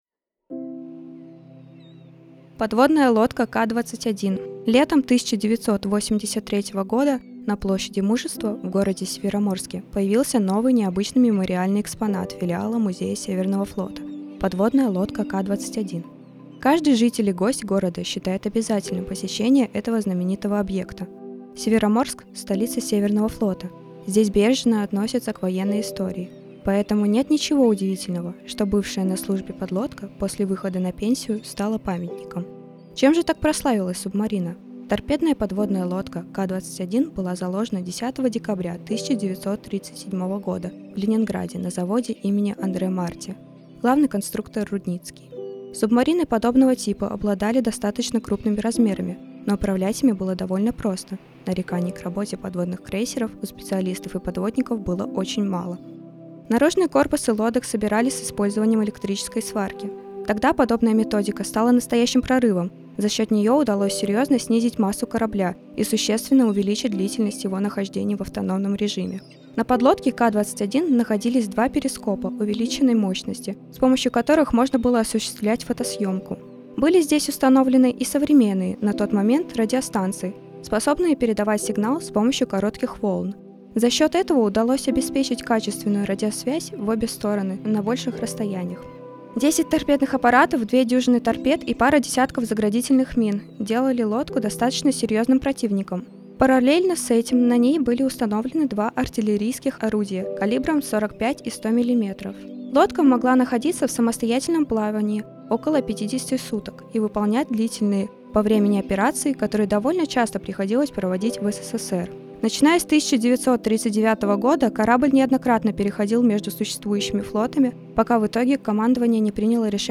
Друзья, предлагаем послушать очередную аудиоэкскурсию в рамках волонтерского туристического проекта «51 история города М»
Текст читает волонтер
Мурманск Трэвел - подлодка К-21 .mp3